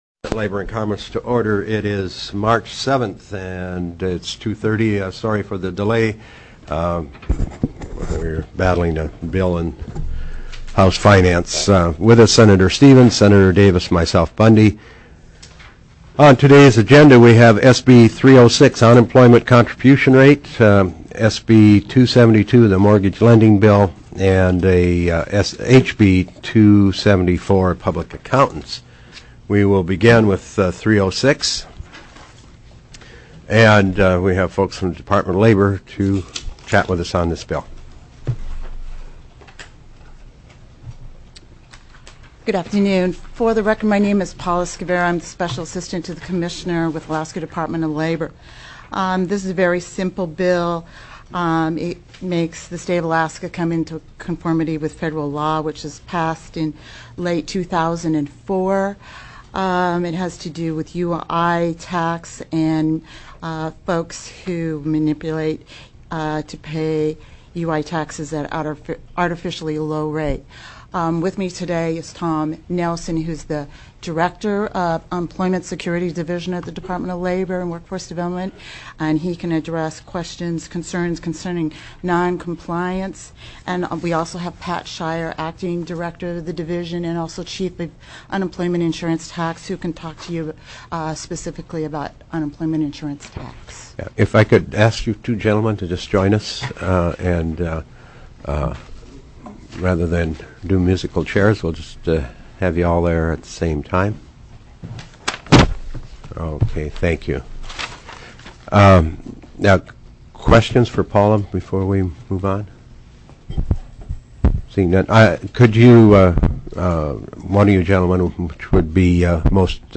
03/07/2006 02:31 PM Senate L&C